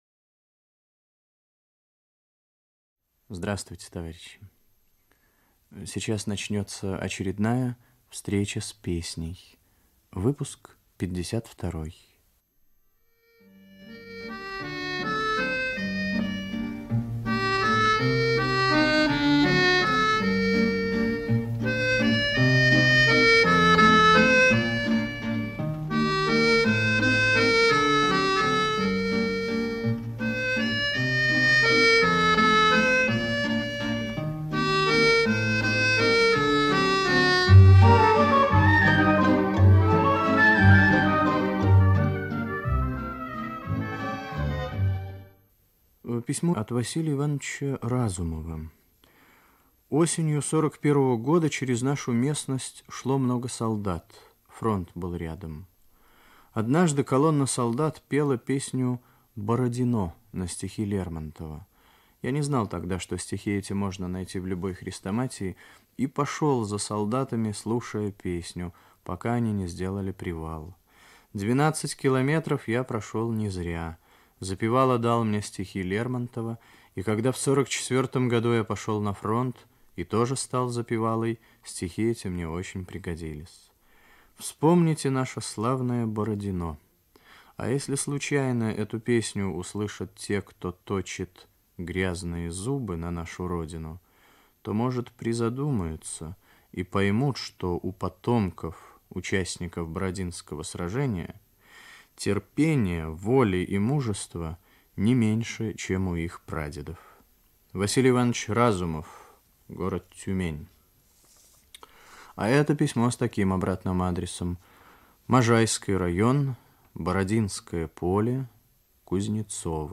Музыкальная заставка к передаче. 2. Русская народная песня
баян.